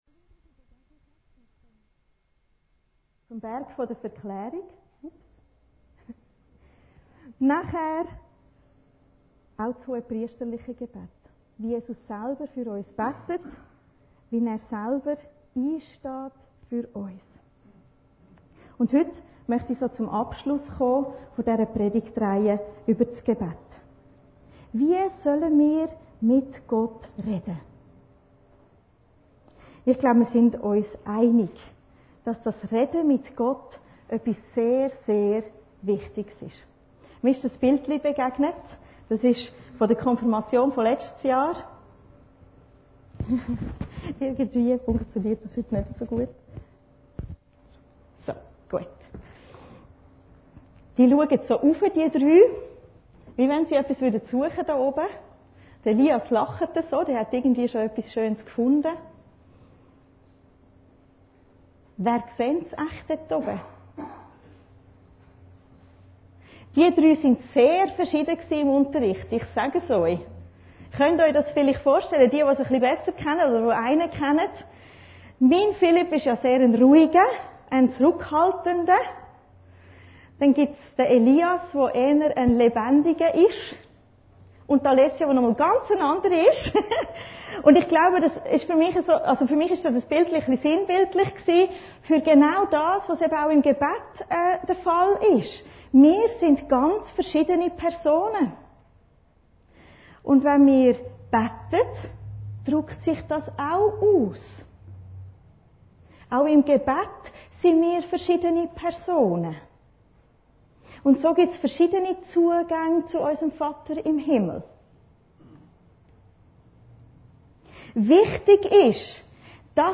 Predigten Heilsarmee Aargau Süd – Reden mit Gott